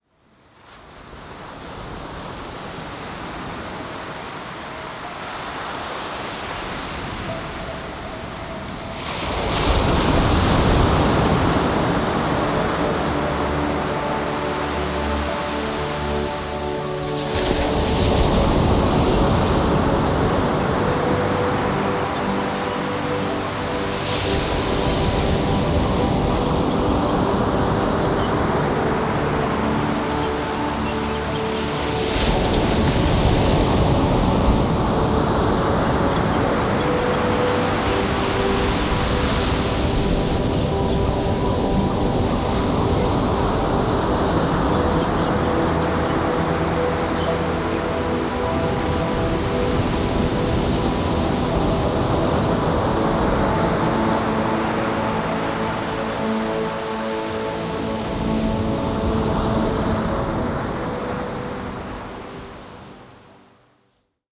Wie ein Tag am karibischen Traumstrand.